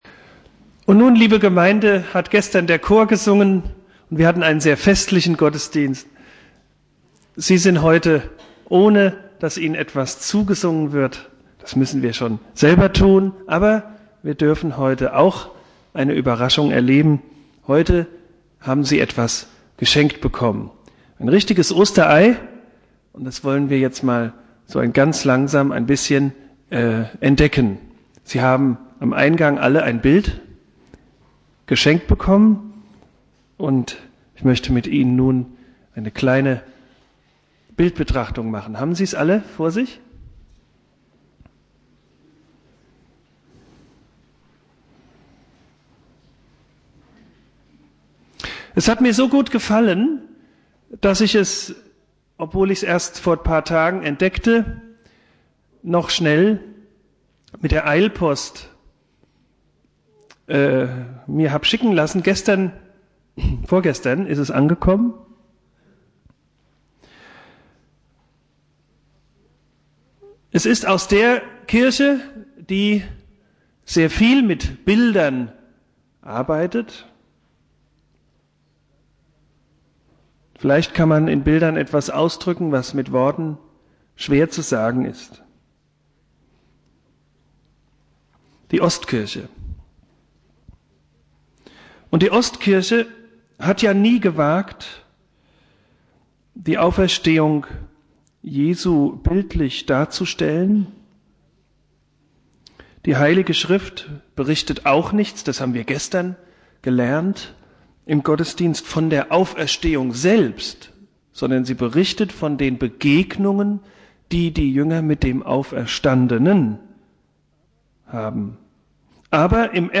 Predigt
Ostermontag